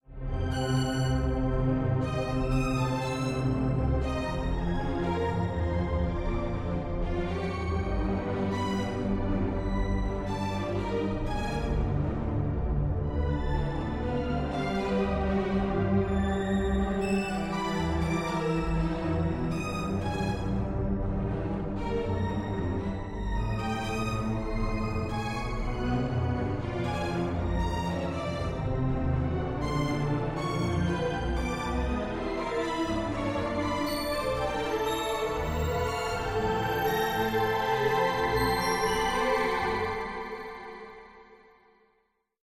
A customer of a cheap online accountants business was so pleased with the saving they made on their tax bill and their accountancy fees that they produced a tune in the accountants honour. The quick tune was more of a gesture of thanks than a serious musical endeavour.